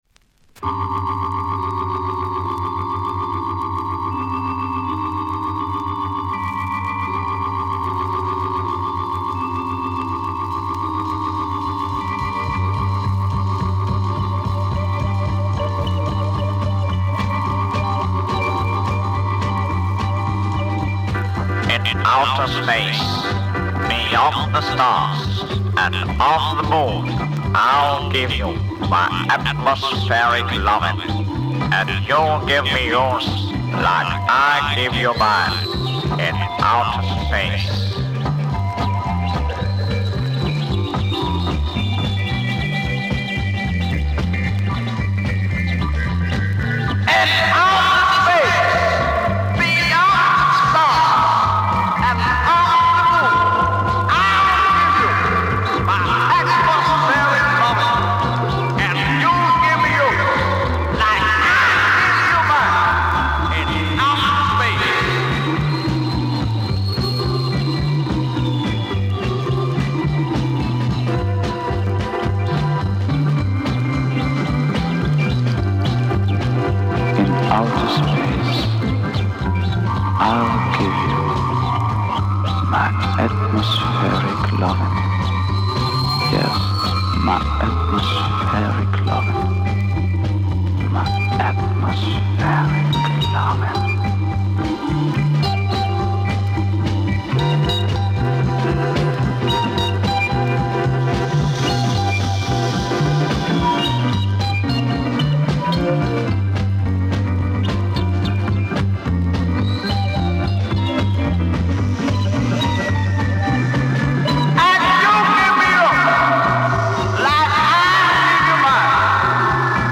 German Freakbeat Psych
Big British sound influence !!!